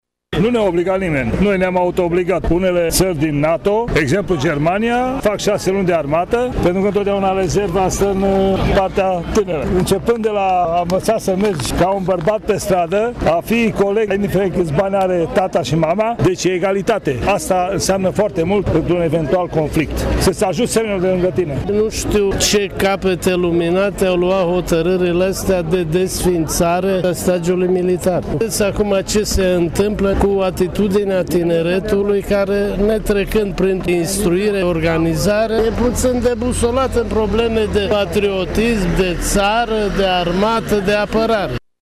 La rândul lor, civilii sunt de acord că un stagiu de armată, chiar redus, ar contribui la disciplinarea tinerelor generații:
vox-armata.mp3